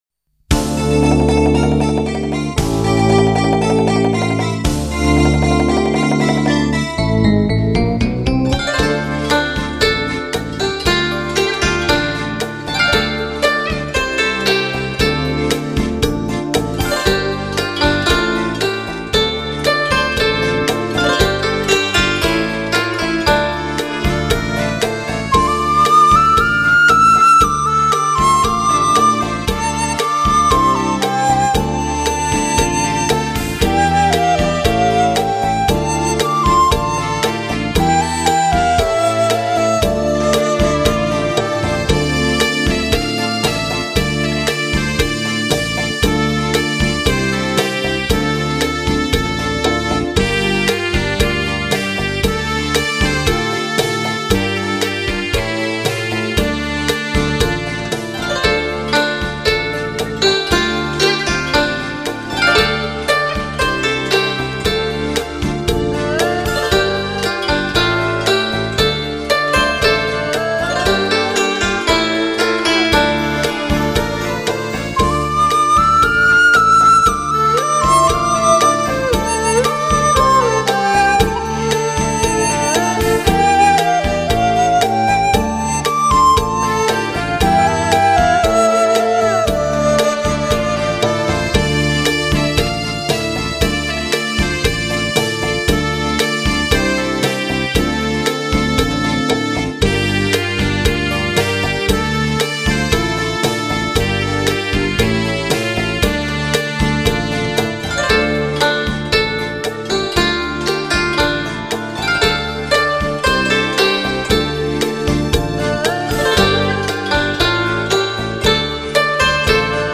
此系列编曲方面比较柔和一些，适合闲情时候欣赏的民乐器轻音乐。
民乐演奏的轻音乐不错听